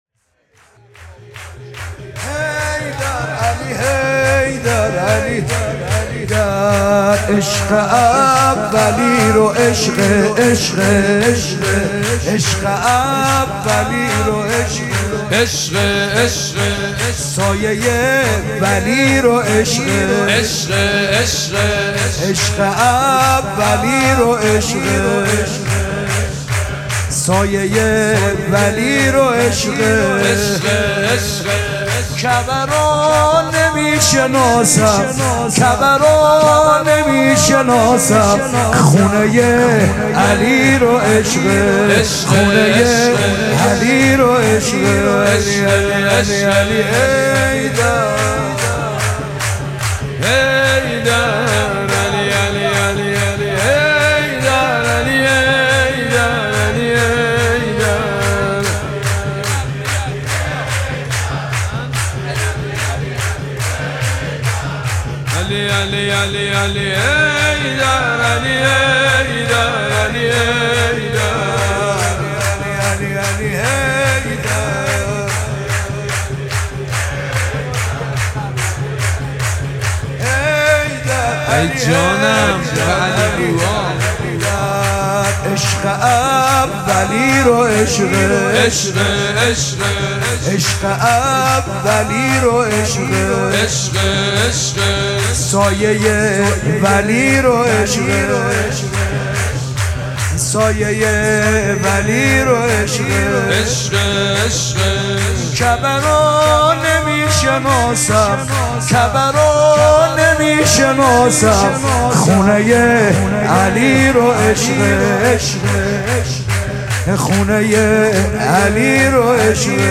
مراسم جشن شام ولادت امام حسن مجتبی(ع)
سرود
مداح